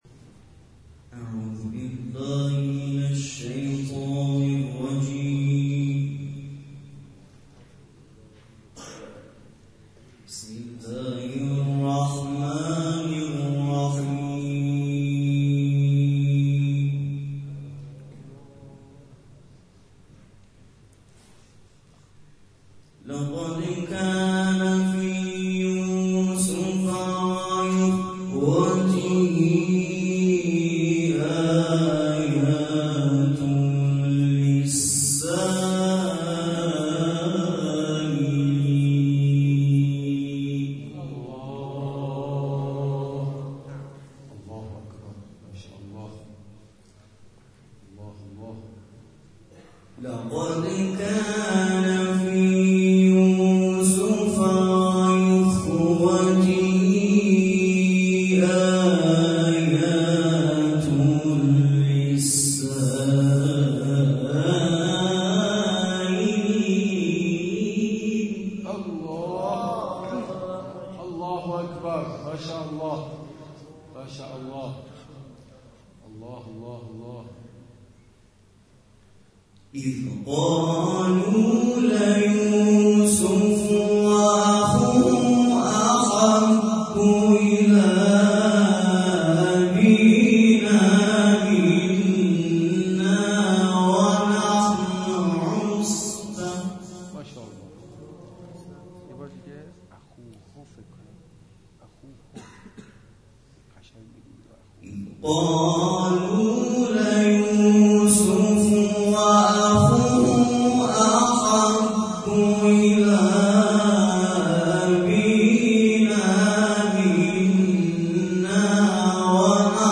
جلسه قرآنی در شهرک غرب با حضور طلاب + صوت و عکس